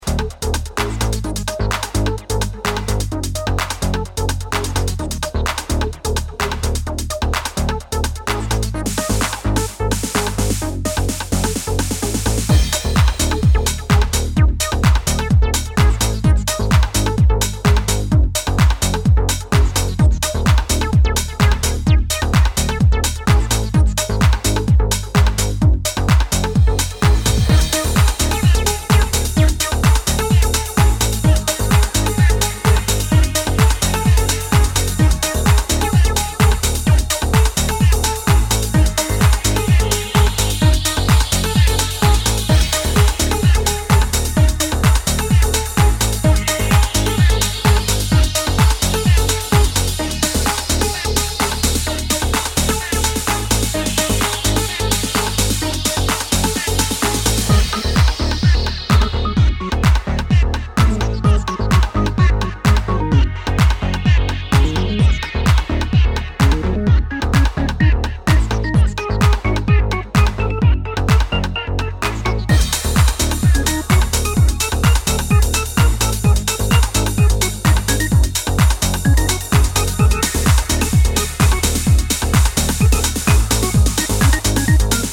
4 dancefloor burners